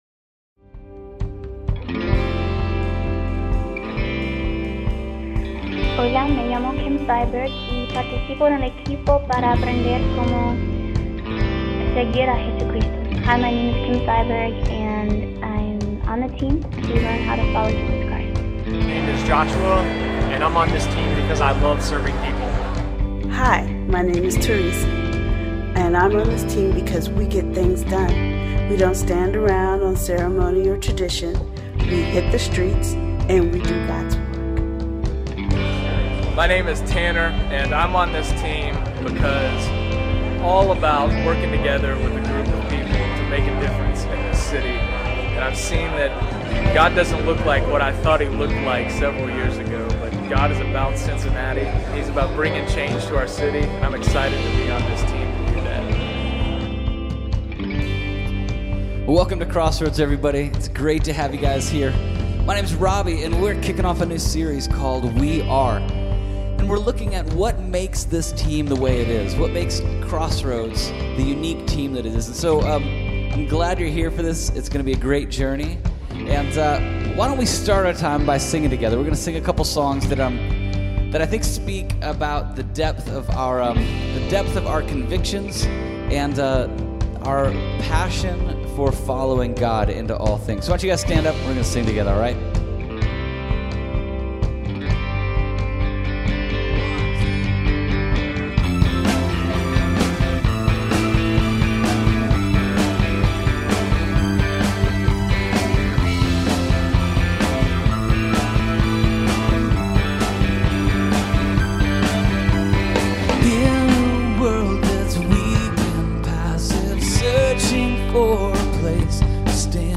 This week is a documentary about what we bleed for, what we live for, what we die for.